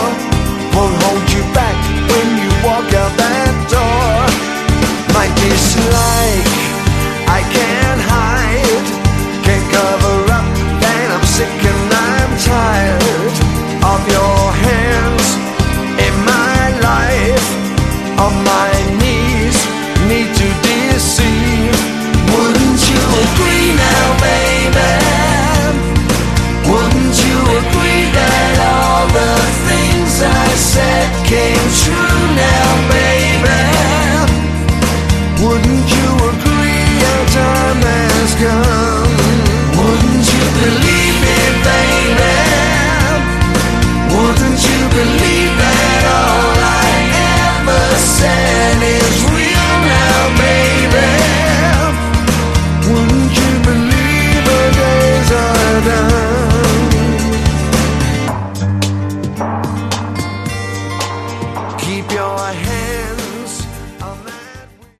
Category: Melodic Hard Rock
Vocals
Guitars, Bass, Backing Vocals
Keyboards